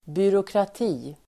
Uttal: [byråkrat'i:]